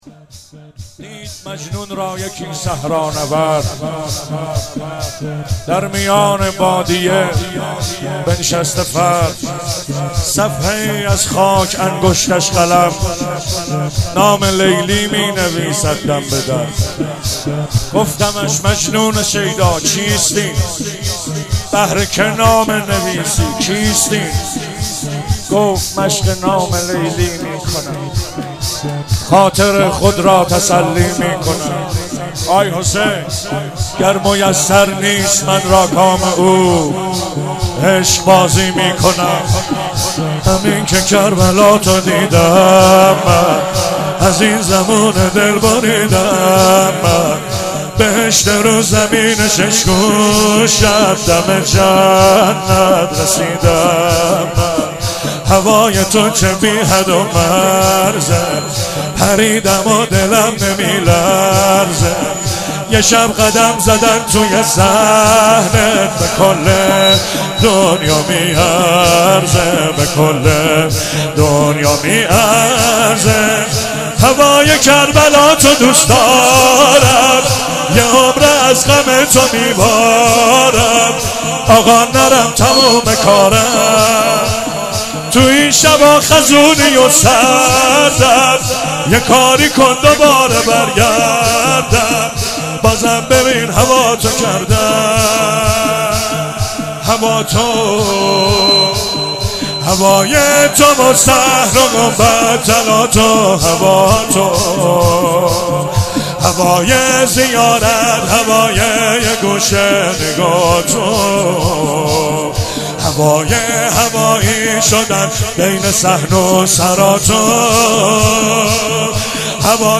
04 heiate alamdar mashhad.mp3